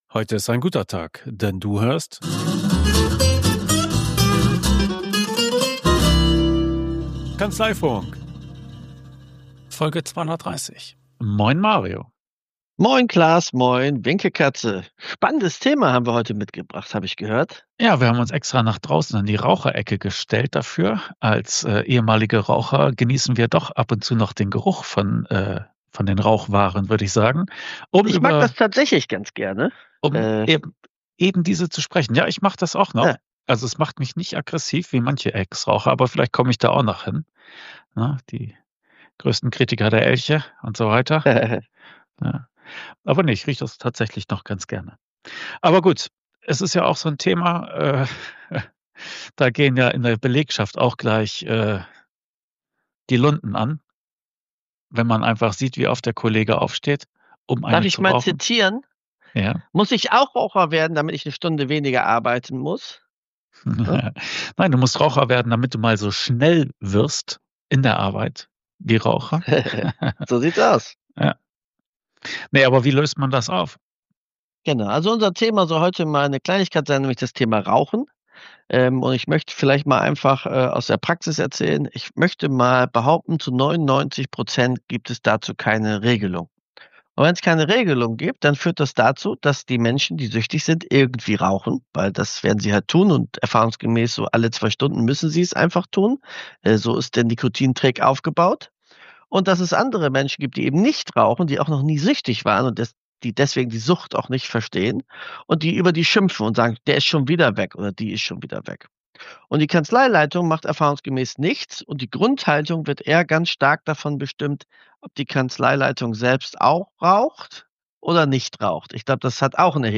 Hier kommen ein paar Empfehlungen zum Umgang mit Raucherpausen und der empfundenen Ungerechtigkeit auf Seiten der Nichtraucher. Praxistipps von zwei Ex-Rauchern.